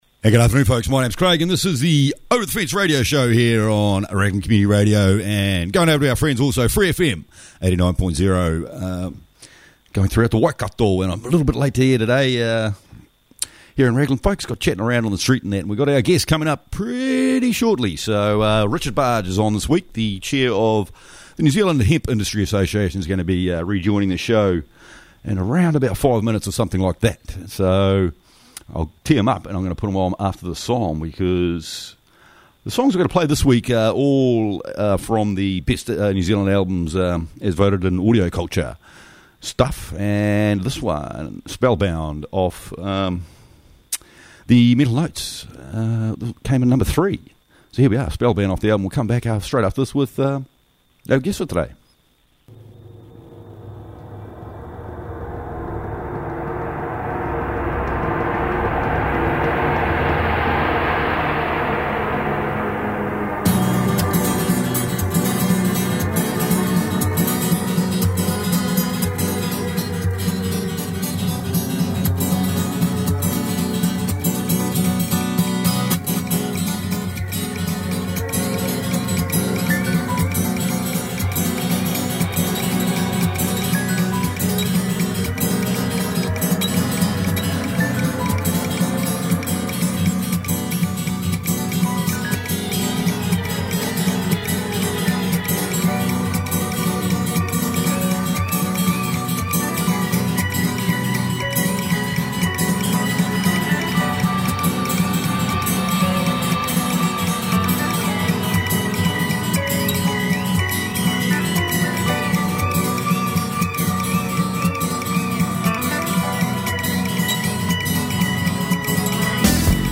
weather and songs from NZs best albums